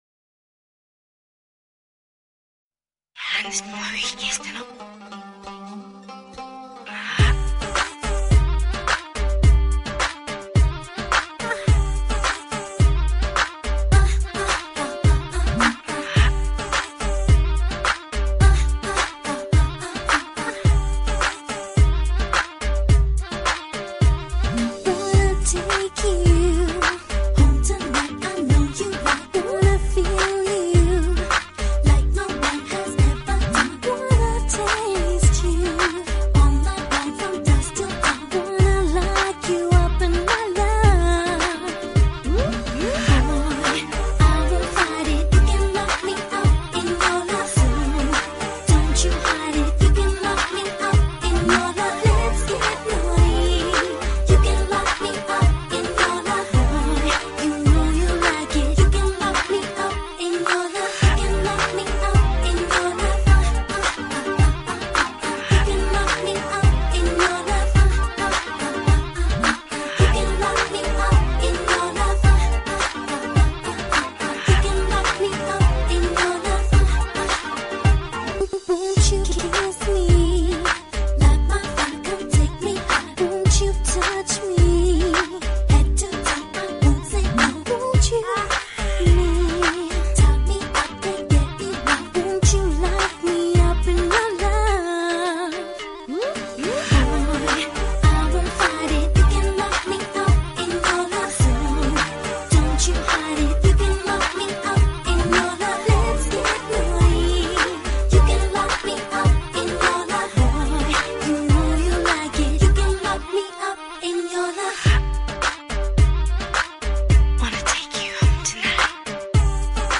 Category: UK Punjabi